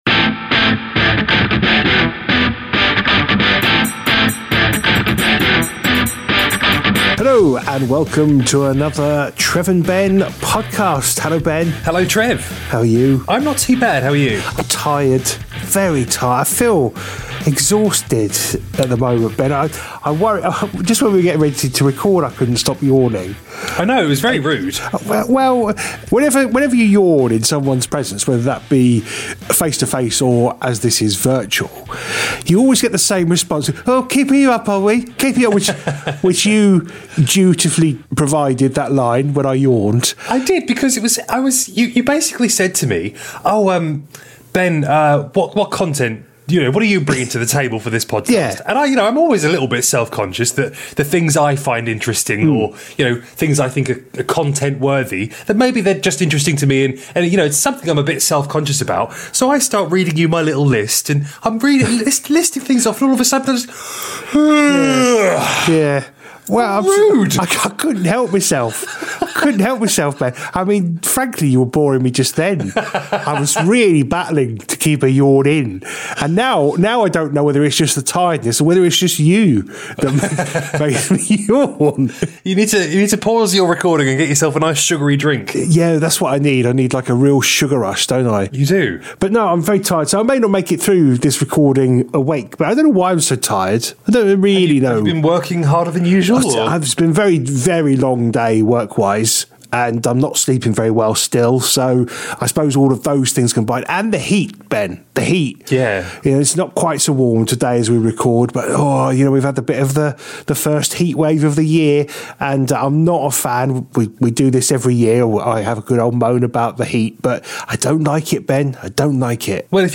This week there's small talk, yawning and sneezing